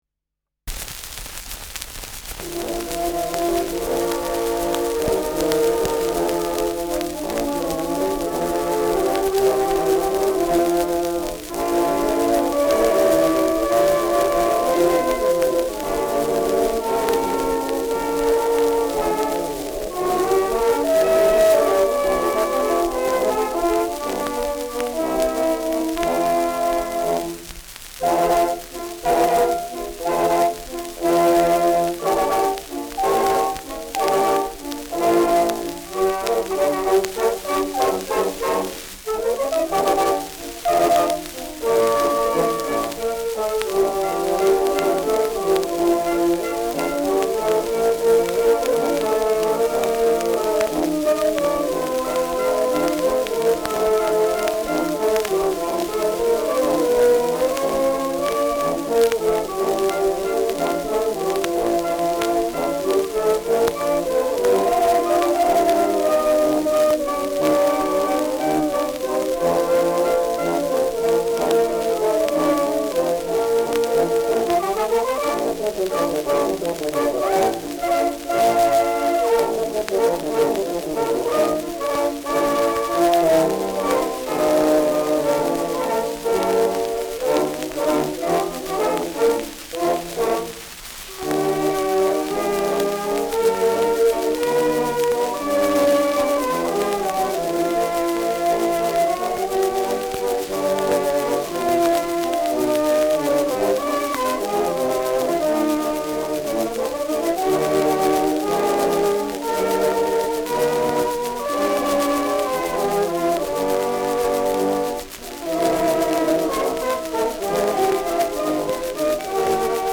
Schellackplatte
präsentes Rauschen : Knistern
Kapelle Peuppus, München (Interpretation)